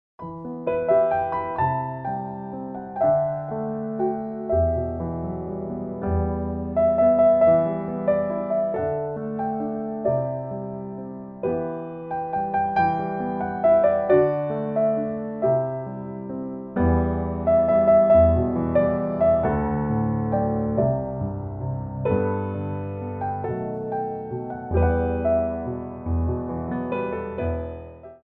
Piano Arrangements of Pop & Rock for Ballet Class
3/4 (8x8)